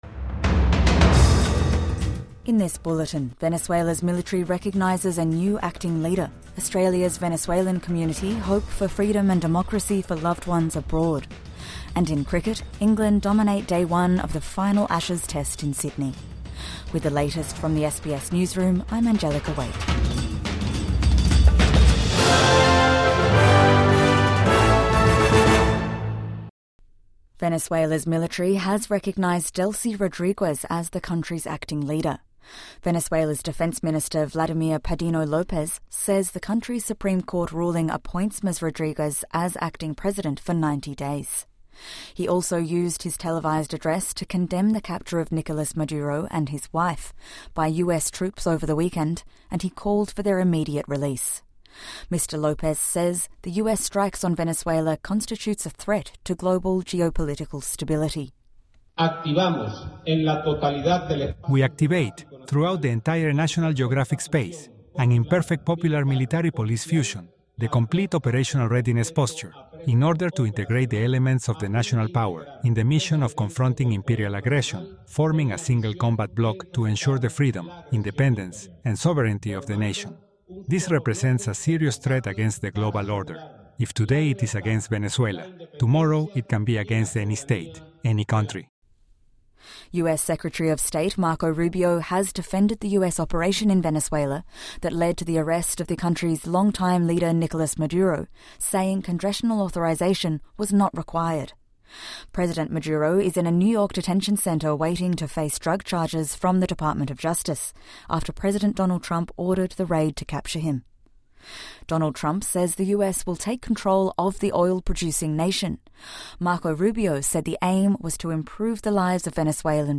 Venezuela's military recognises a new acting leader | Morning News Bulletin 5 January 2026